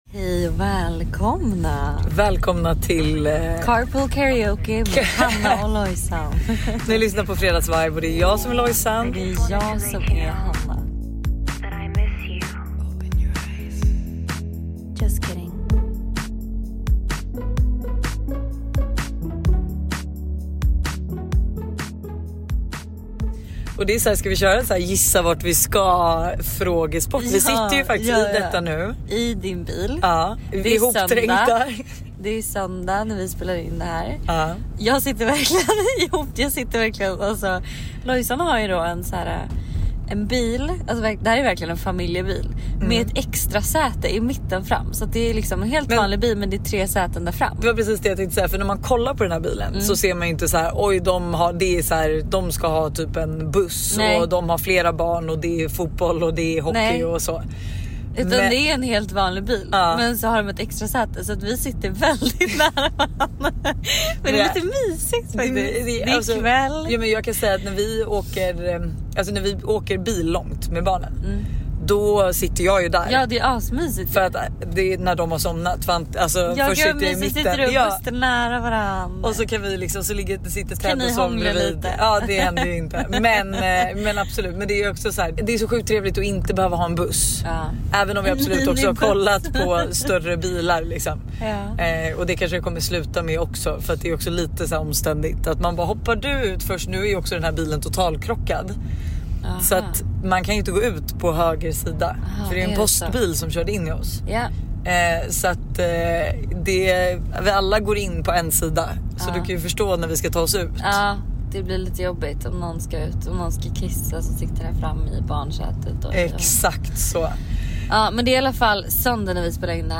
… continue reading 439 Episoden # Samtal # Bauer Media # Samhällen